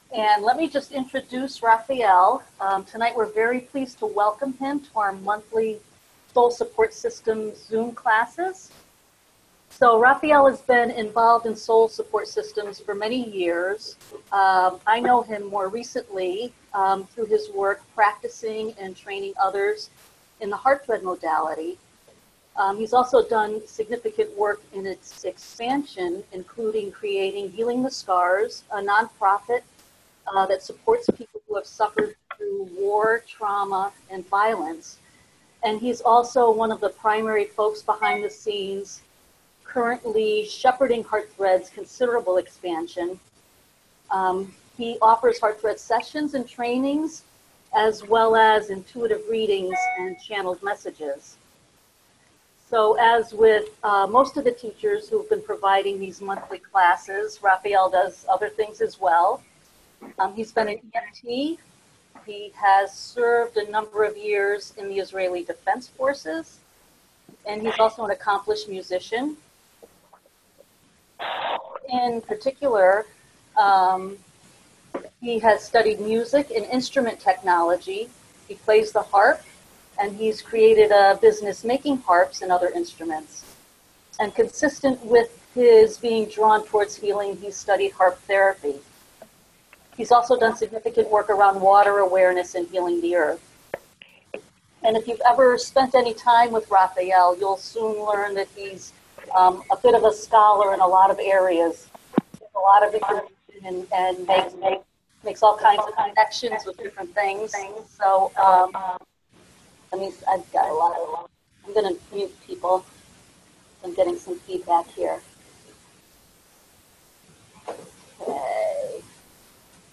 This online course was offered on the 28th May, 2019